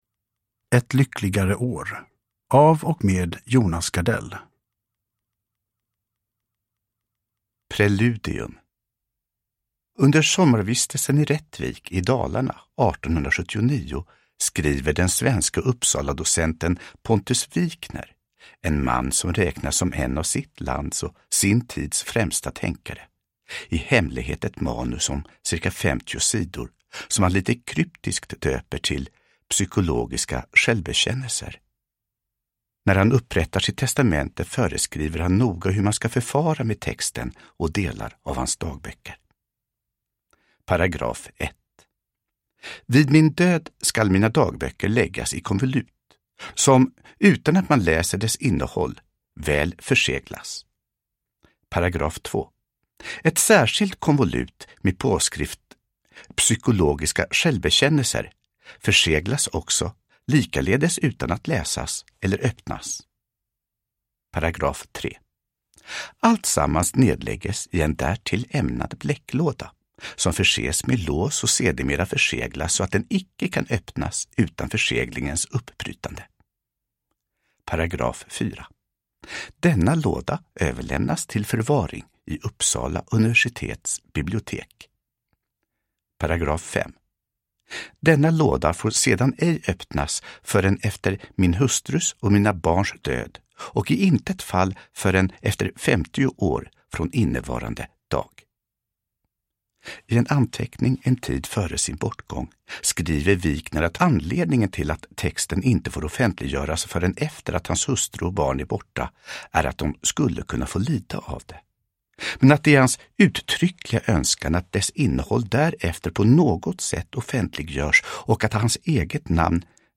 Ett lyckligare år (ljudbok) av Jonas Gardell